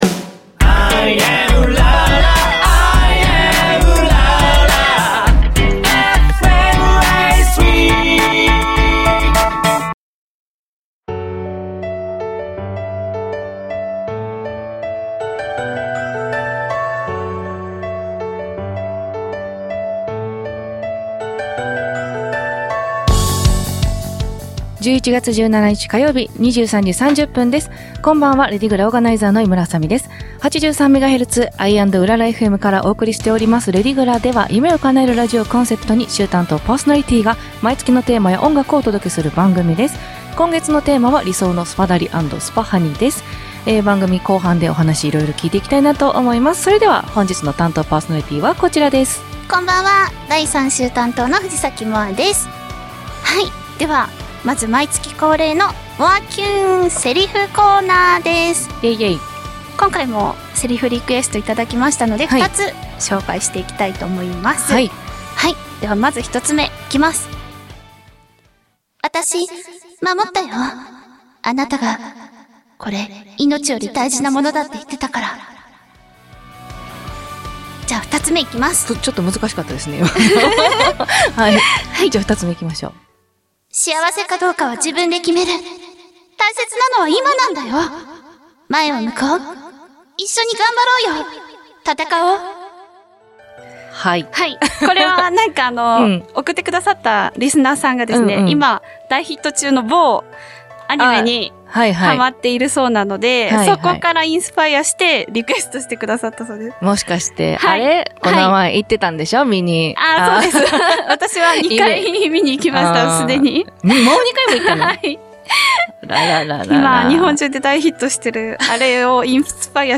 『夢を叶えるラジオ』をコンセプトに、夢を叶えるため頑張るお話や、毎月変わるテーマについて、週替わりパーソナリティーやゲストと共に、お便りやメッセージなどをお届けする番組。また、番組の冒頭と最後に毎月全４週で完結するラジオドラマを放送。エンディング曲は毎月変わります。